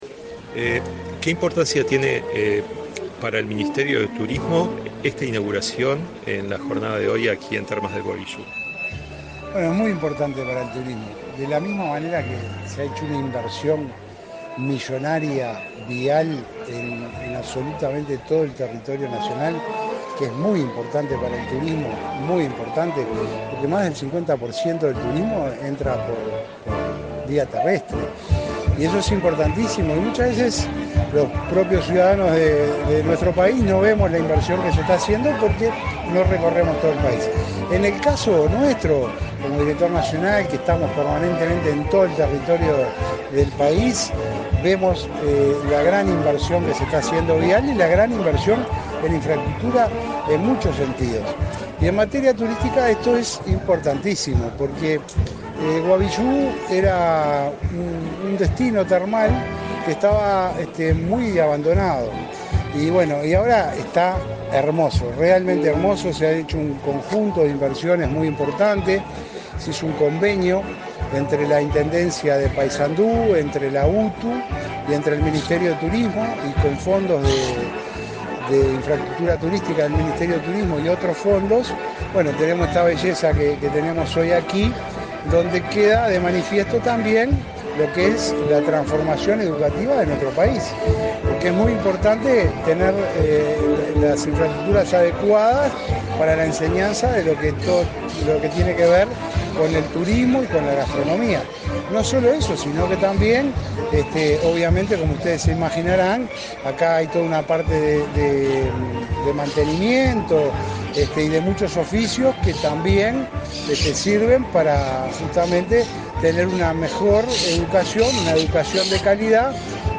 Entrevista al director Nacional de Turismo, Roque Baudean
Entrevista al director Nacional de Turismo, Roque Baudean 24/06/2024 Compartir Facebook X Copiar enlace WhatsApp LinkedIn La Administración Nacional de Educación Pública y la Dirección General de Educación Técnico-Profesional inauguraron, este 24 de junio, el Centro de Especialización Hotelera, en Paysandú. Tras el evento, el director Nacional de Turismo, Roque Baudean, realizó declaraciones a Comunicación Presidencial.